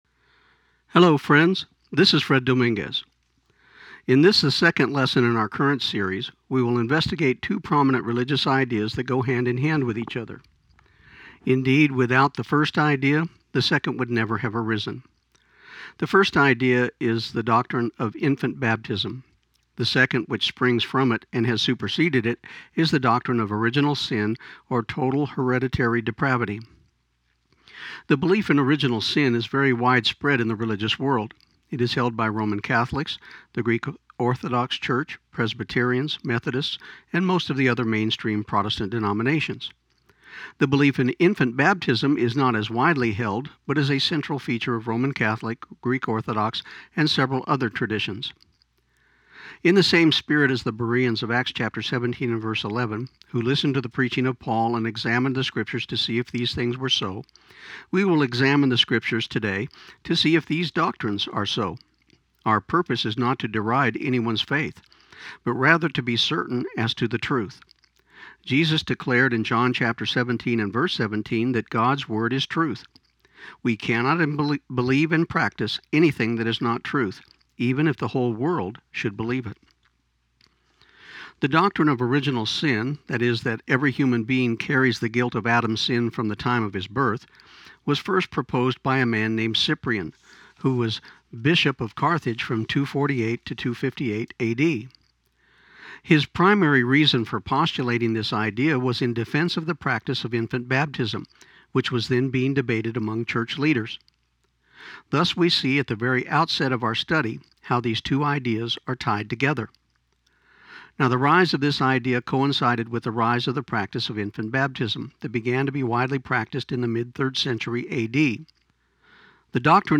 This program aired on KIUN 1400 AM in Pecos, TX on May 13, 2015.